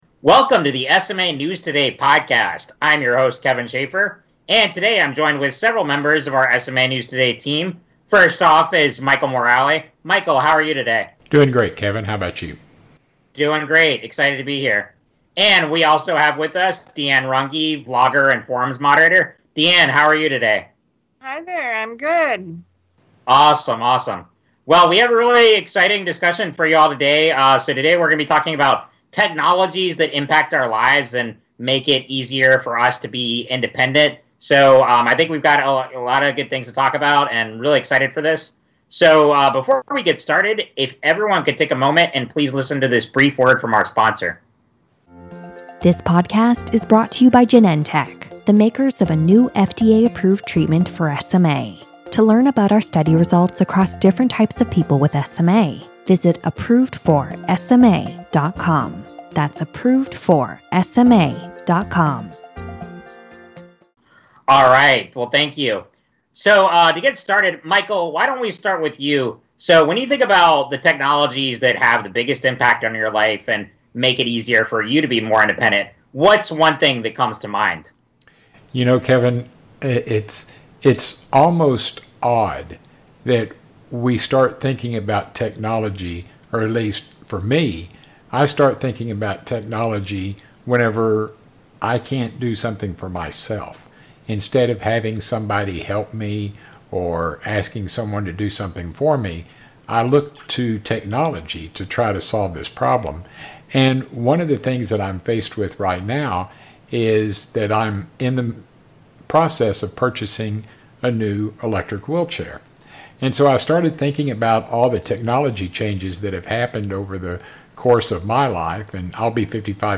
Roundtable Podcast Discussion on Technology
In this episode, members of the SMA News Today team discuss assistive technologies that have had a unique impact on their lives. These technologies include electric wheelchairs, Amazon Echo devices, the JACO robotic arm, adaptive vehicles, and more.